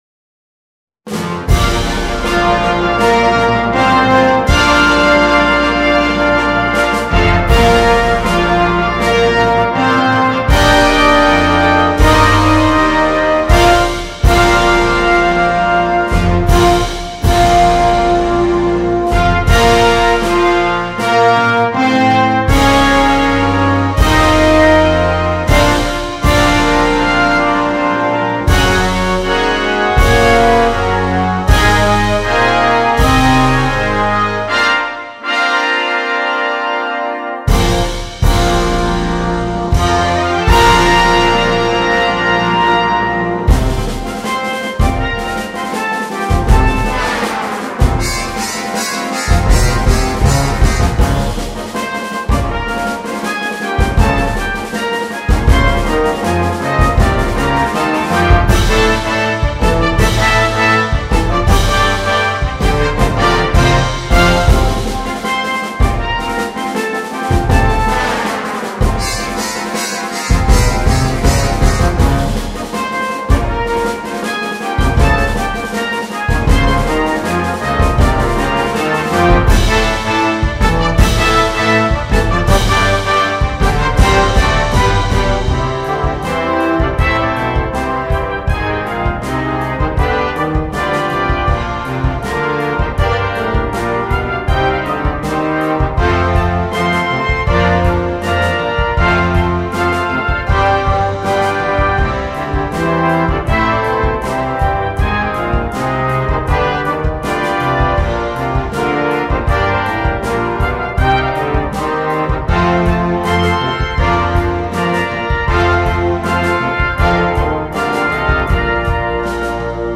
2. ブラスバンド
フルバンド
ソロ楽器なし
元の組成, 軽音楽